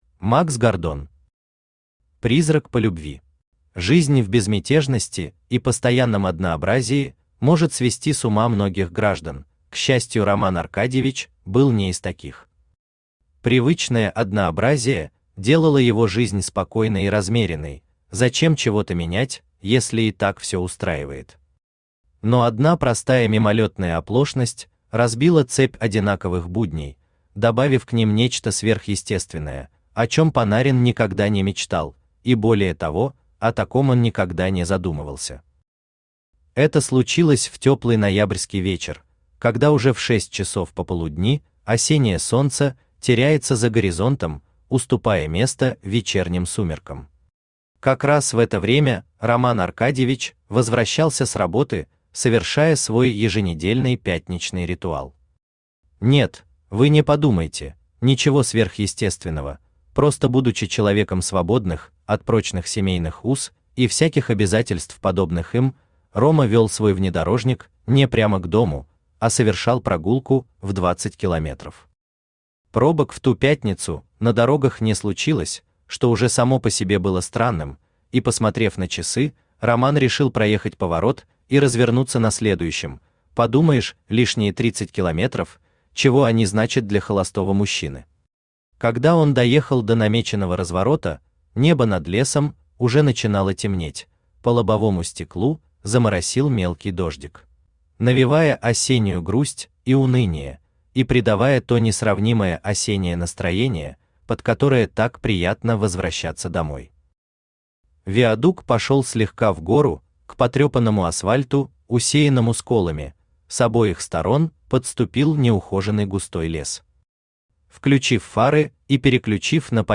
Aудиокнига Призрак по любви Автор Макс Гордон Читает аудиокнигу Авточтец ЛитРес.